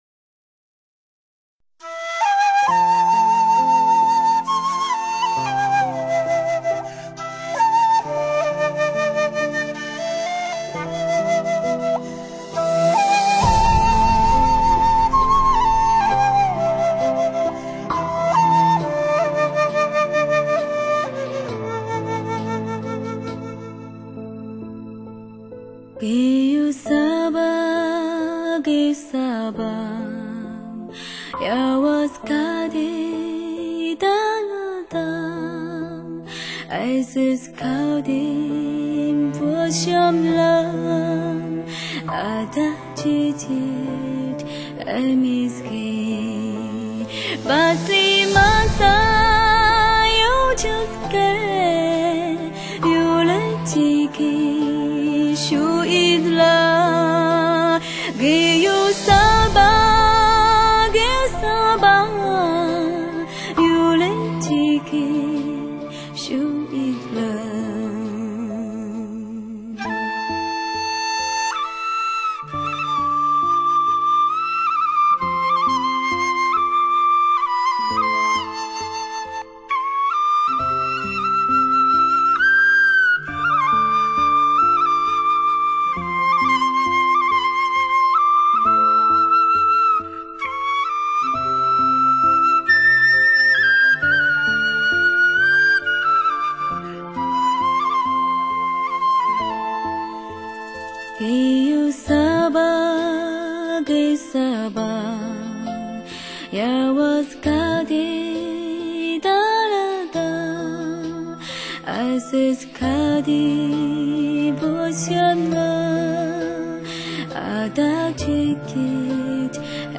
原始天然的声线 干净通透的嗓音 彩云之南的自然奇迹 发烧领域的唯一惊奇
笛子的忧怨，奏出了大漠的荒凉；人声的哀伤，写出了世间的无奈。